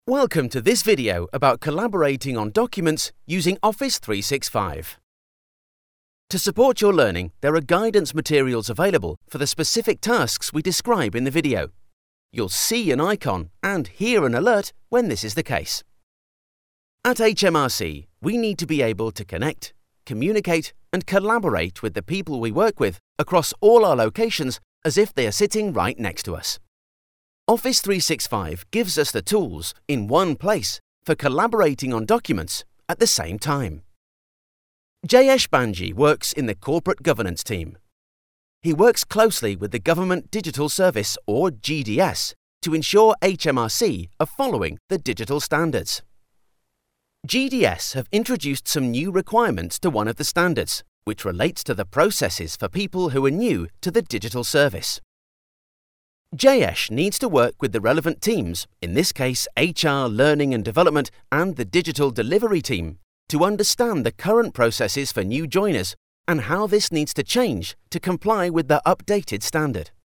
British chap, friendly voice, bright and engaging. Sounds like fun.
e-learning demo